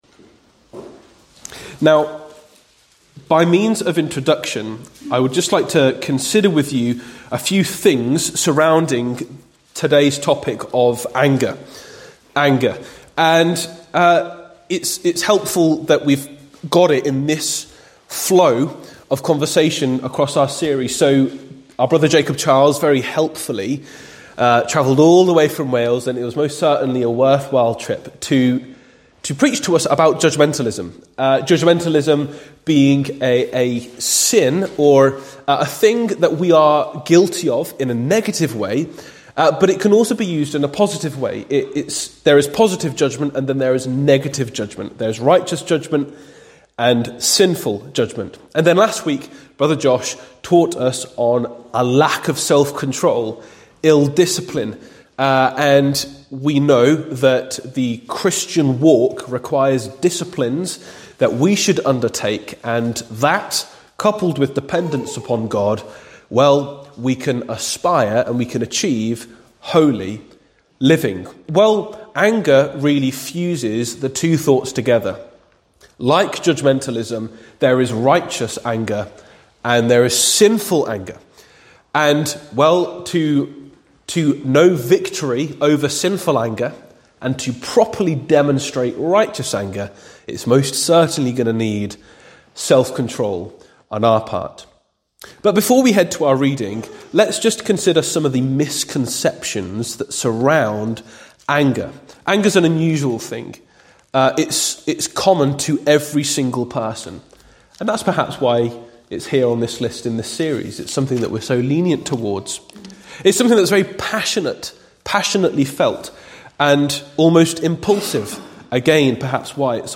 Instead, we discover that anger can be sinful or righteous. Have a listen to this sermon to learn what the Bible says about anger.
Bible-Class-Anger-43-mins-1.mp3